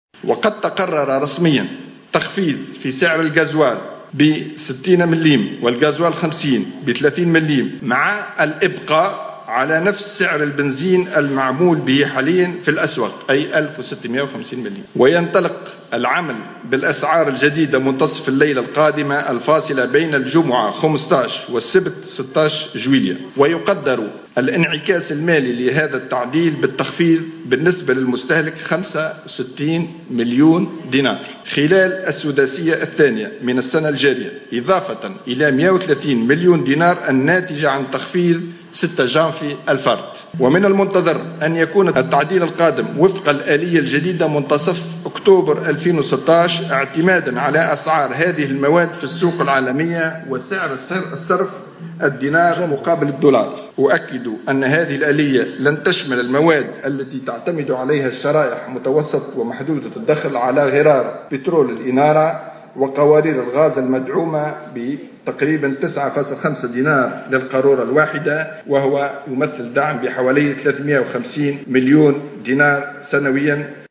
وافاد مرزوق، خلال ندوة صحفية، الخميس بالقصبة، ان العمل بهذه الأسعار الجديدة ينطلق مع منتصف الليلة الفاصلة بين الجمعة 14 جويلية والسبت 15 جويلية 2016